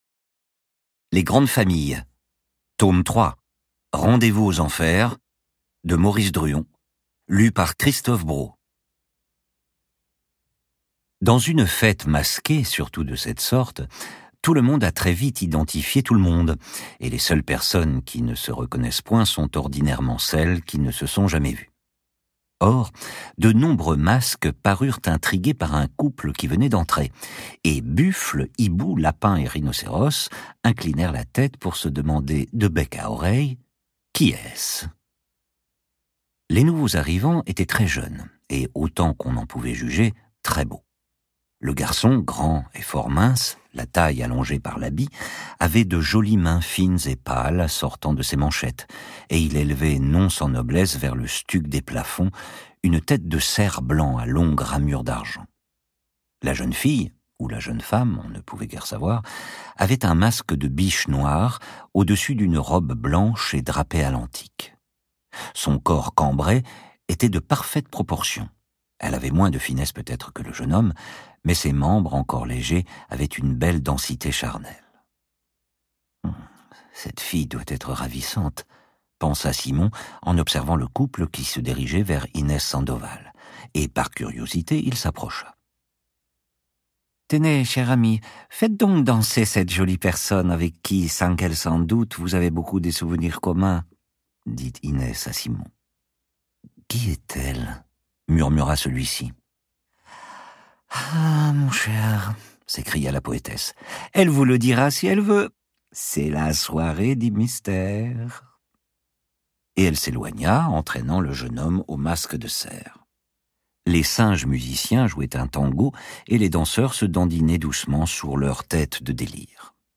je découvre un extrait